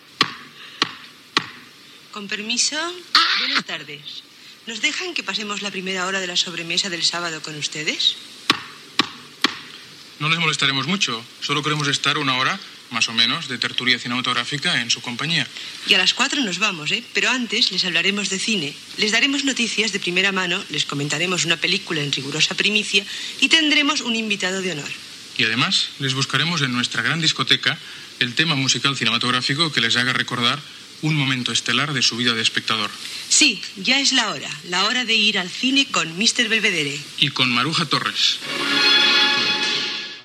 Presentació del programa
Extret de Crònica Sentimental de Ràdio Barcelona emesa el dia 29 d'octubre de 1994.